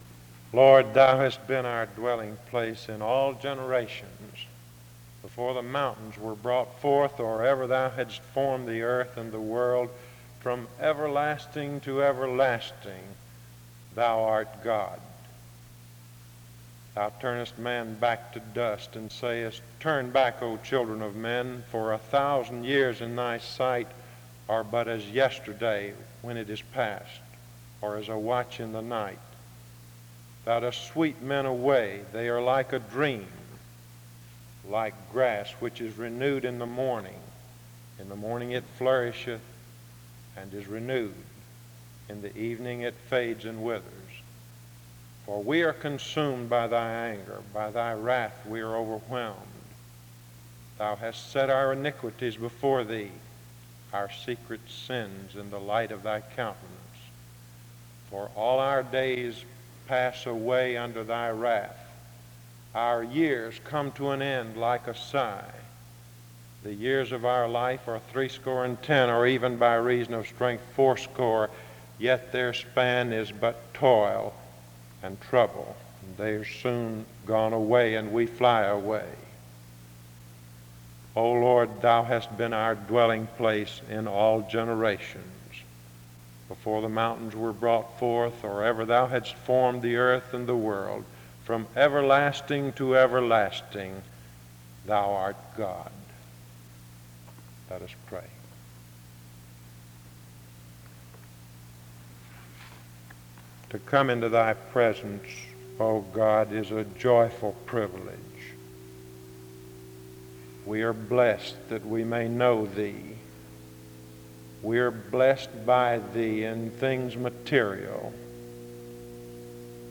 The service begins with Scripture reading from 0:00-1:40. A prayer is offered from 1:40-4:00.
Music plays from 4:10-4:48. An introduction to the speaker is given from 4:55-6:12. Special music plays from 6:26-10:08.
He states that the Church should care about people. A closing prayer is offered from 35:23-36:10. Music closes out the service from 36:10-36:50.